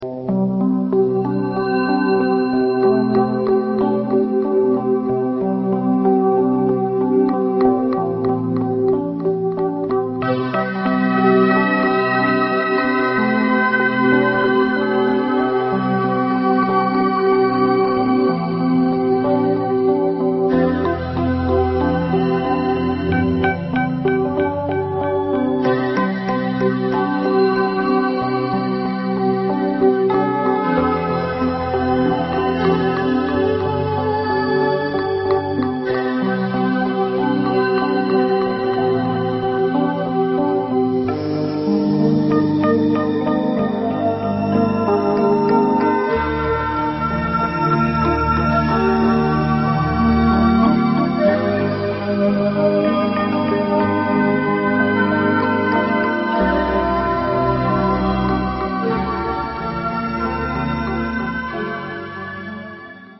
纯音乐